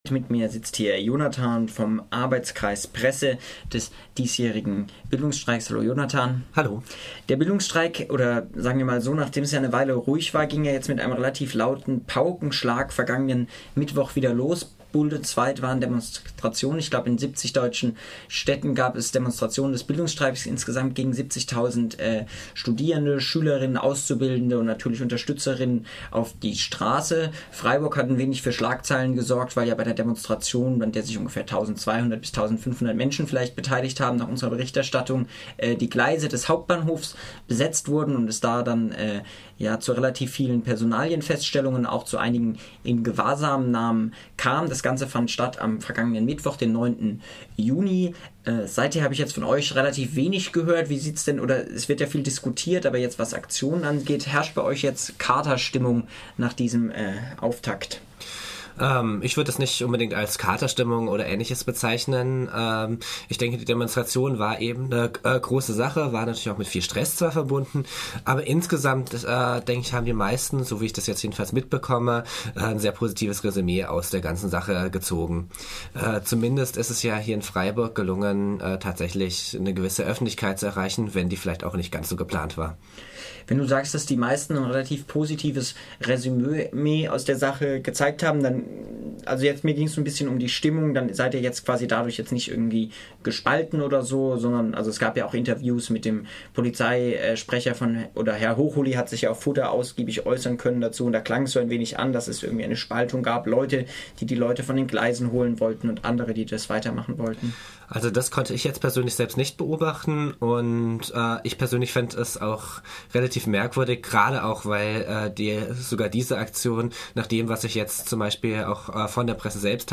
Interview mit einem Vertreter des "AK Presse" des Bildungstreiks zur Demo vom 09. Juni und